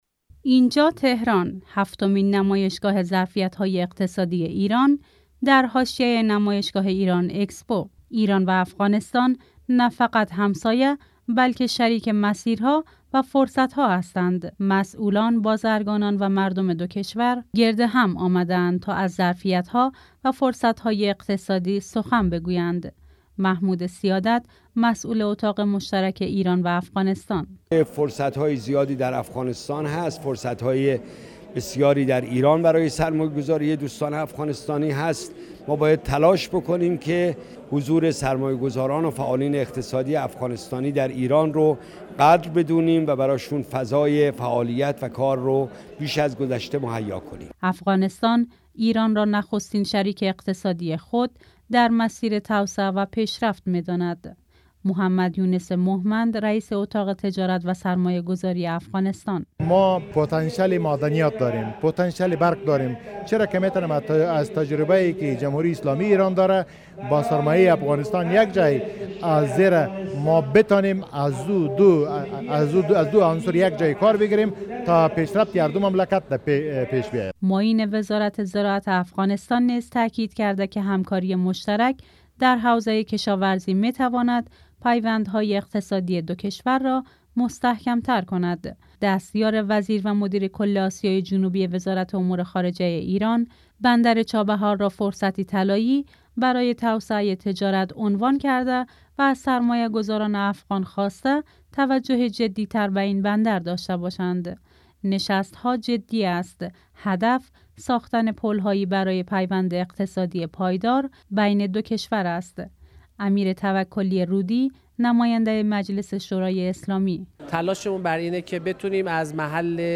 هفتمین همایش ظرفیت های اقتصادی ایران و افغانستان با حضور تاجران و فعالان اقتصادی دو کشور در حاشیه نمایشگاه ایران اکسپو 2025 در تهران برگزار شد.